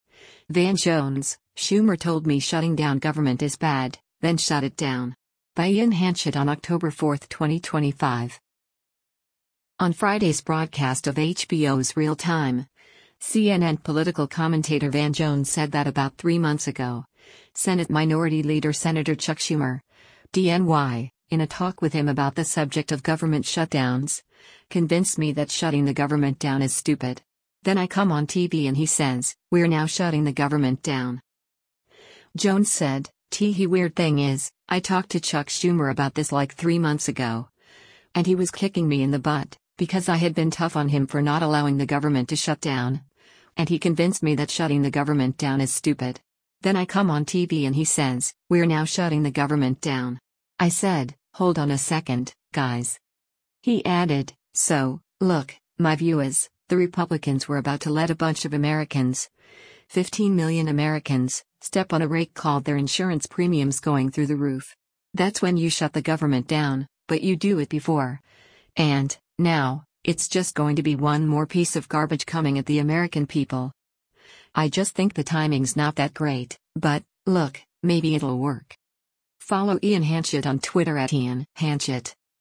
On Friday’s broadcast of HBO’s “Real Time,” CNN Political Commentator Van Jones said that about three months ago, Senate Minority Leader Sen. Chuck Schumer (D-NY), in a talk with him about the subject of government shutdowns, “convinced me that shutting the government down is stupid. Then I come on TV and he says, we’re now shutting the government down.”